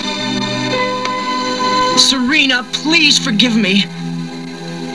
Darien - Darien (Tuxedo Mask) saying "Serena please Forgive Me"